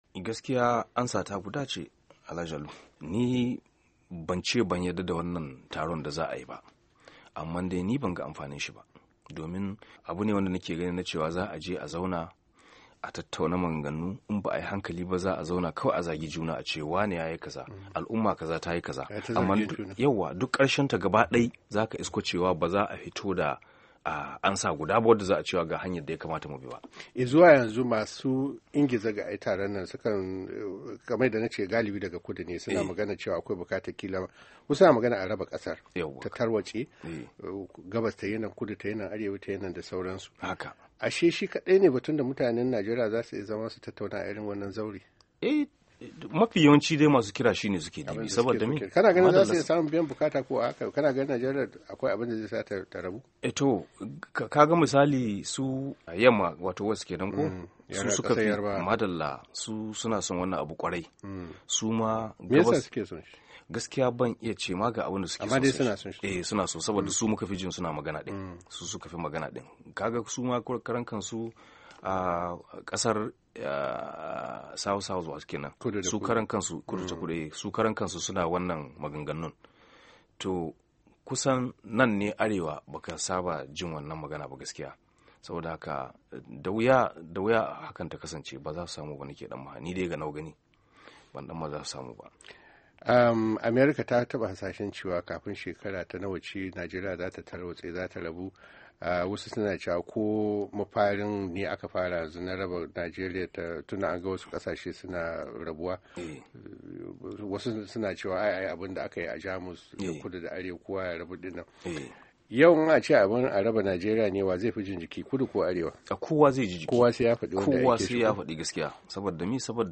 Hira Da Saneta Ahmed Muhammad Maccido - 3:50